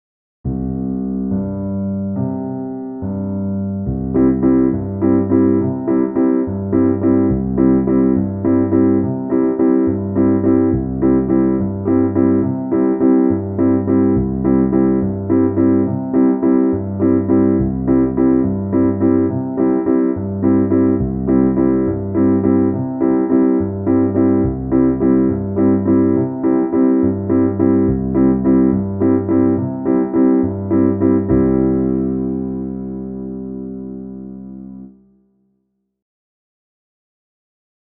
Tonalidade: do lidio; Compás 6/8
Acompanhamento_1_Lidio.mp3